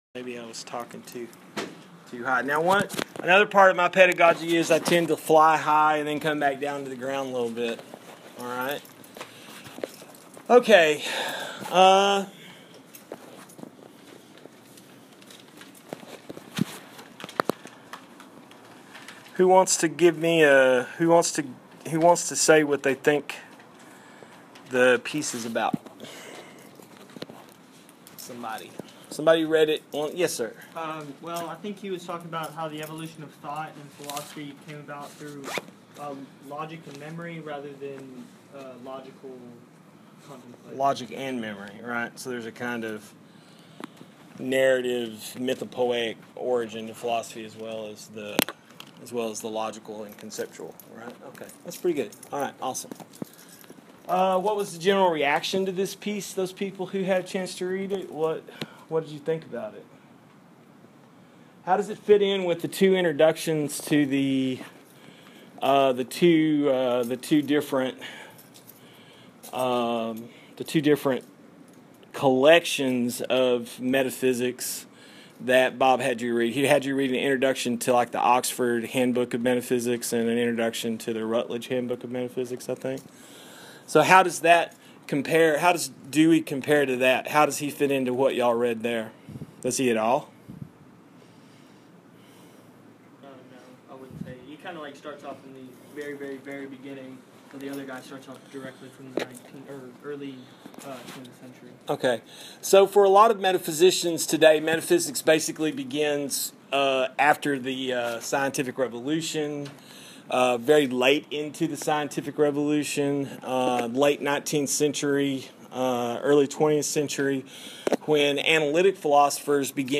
Had some good discussions with the folks in the class.